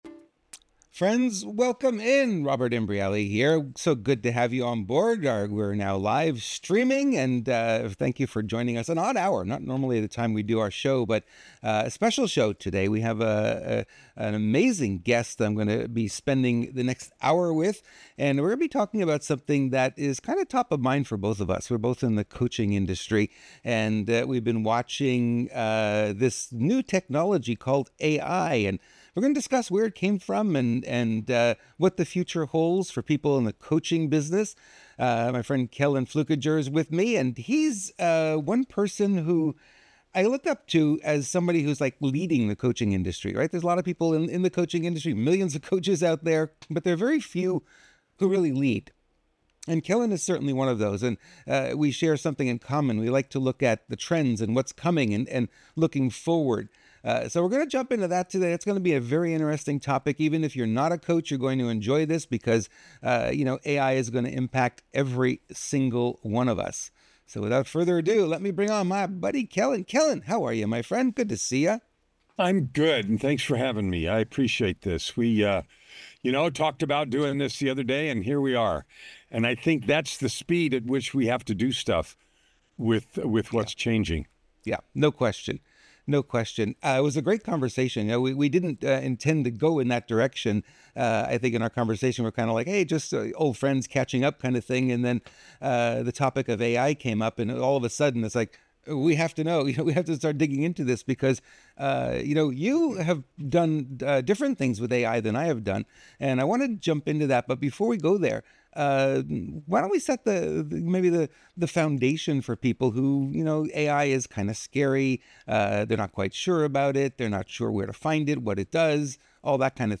a dynamic discussion